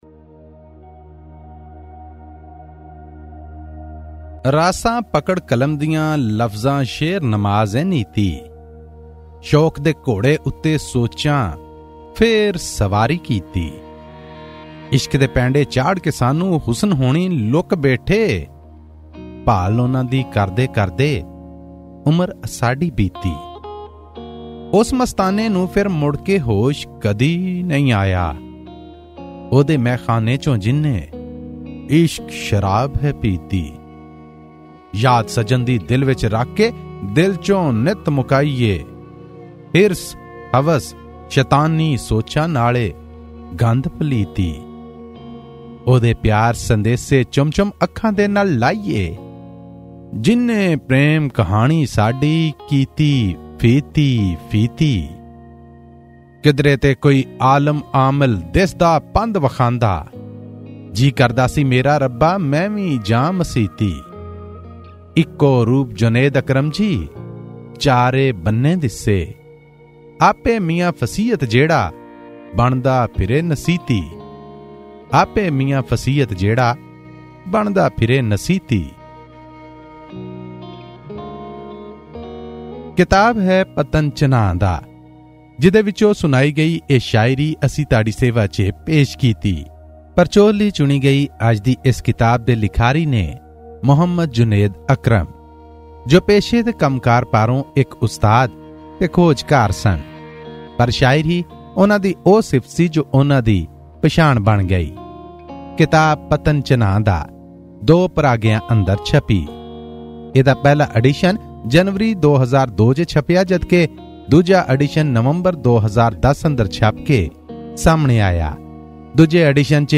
Book review